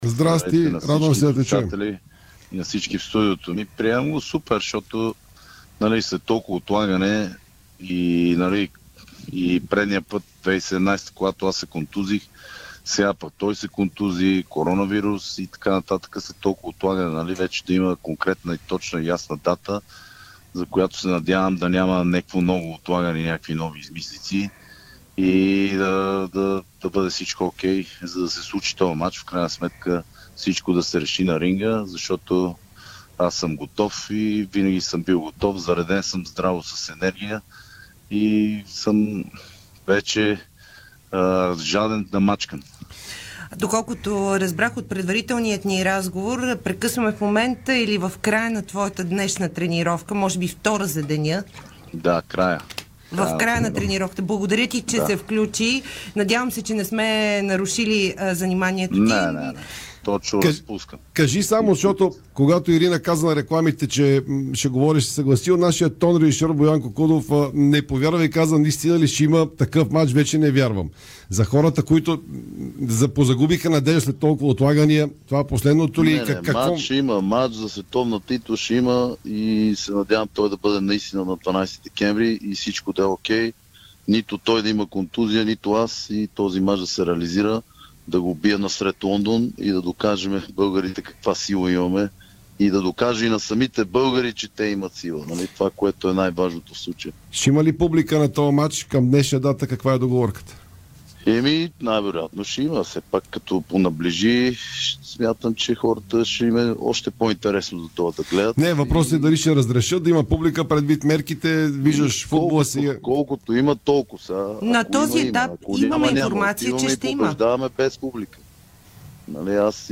Най-добрият български боксьор даде интервю пред Дарик и dsport, след като стана ясно, че датата 12 декември е окончателна за двубоя му срещу Антъни Джошуа в зала 02 в Лондон.